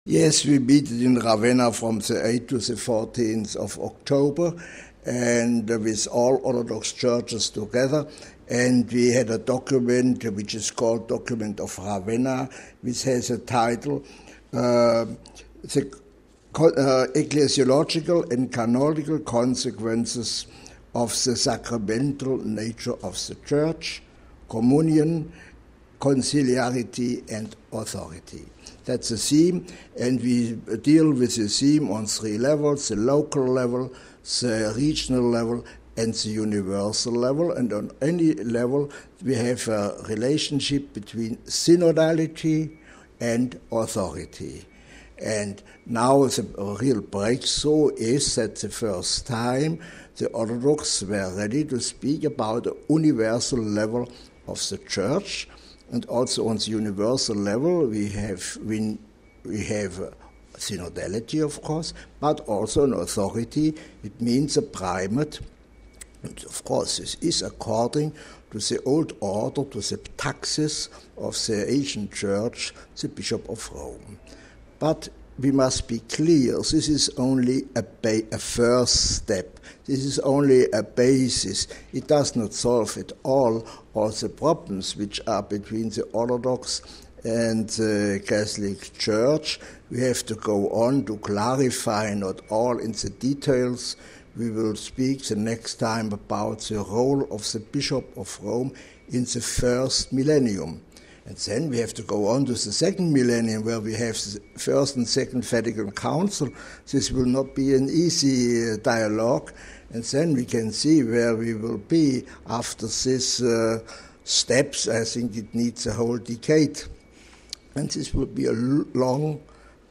Cardinal Walter Kasper, president of the Vatican Council who led the Catholic delegation at the meeting spoke to us about the main issues contained in the document.